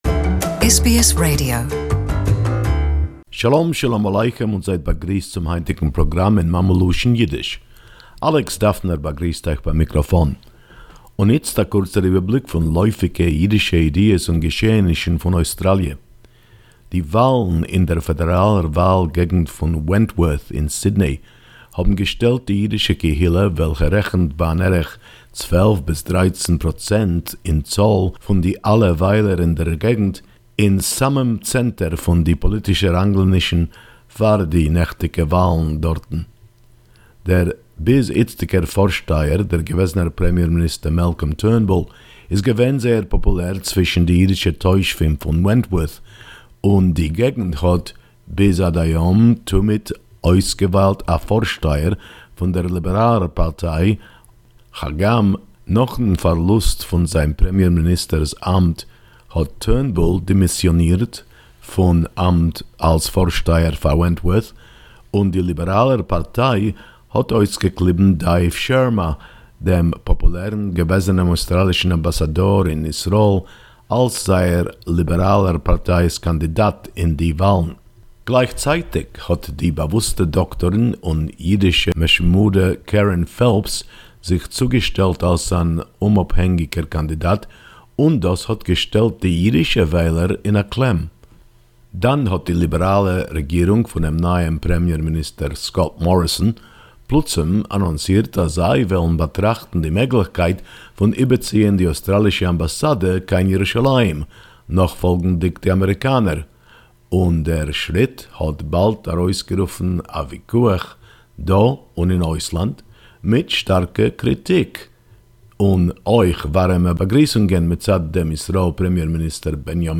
Yiddish report Jewish community of Wentworth, placed at the centre of the domestic & international controversy 21.10.2018